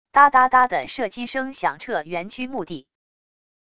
哒哒哒的射击声响彻园区墓地wav音频